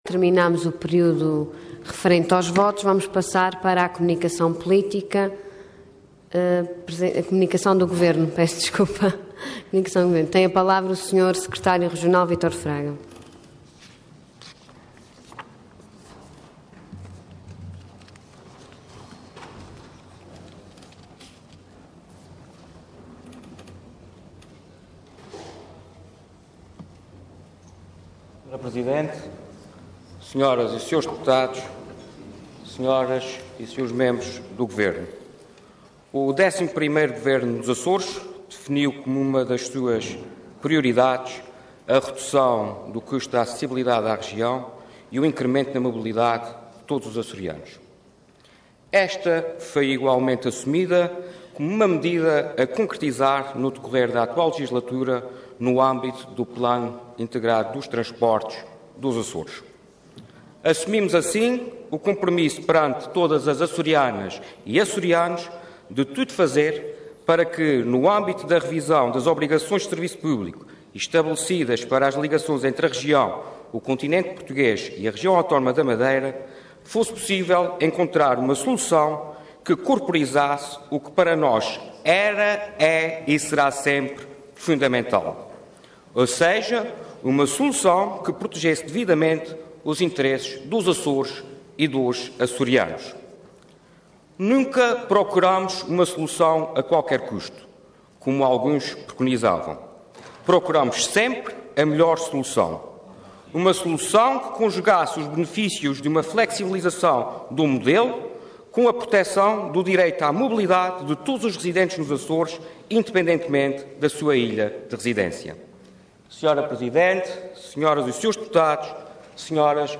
Detalhe de vídeo 9 de setembro de 2014 Download áudio Download vídeo X Legislatura Redução do custo da acessibilidade à região Intervenção Comunicação do Governo Orador Vítor Ângelo de Fraga Cargo Secretário Regional do Turismo e Transportes Entidade Governo